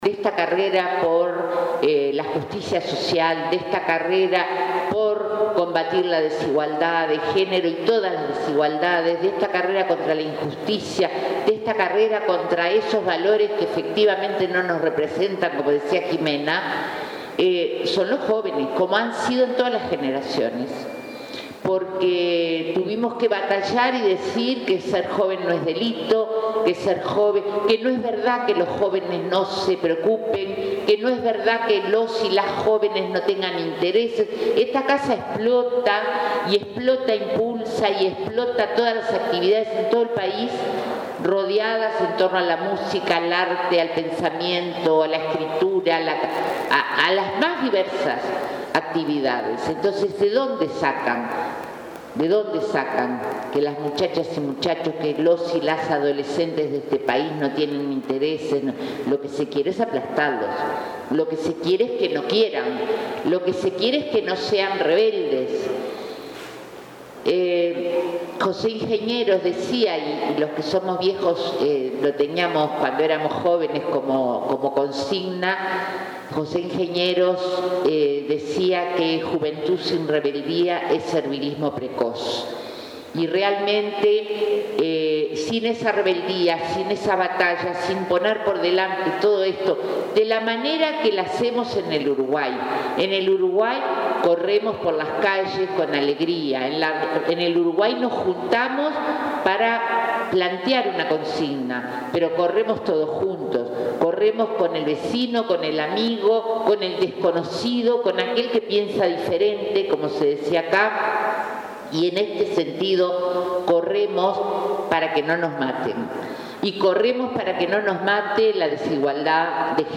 “De dónde sacan los que afirman que los adolescentes no tienen intereses”, afirmó la ministra del Mides, Marina Arismendi, argumentando que en las actividades que realiza el INJU “explota de jóvenes”. “Lo que buscan es aplastarlos, que no sean rebeldes”, dijo en el lanzamiento de la 5K del INJU que este año tiene la consigna “Nos mata la desigualdad de género”.